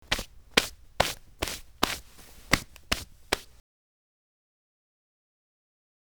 household
Cloth Brushing Off Jeans with Hands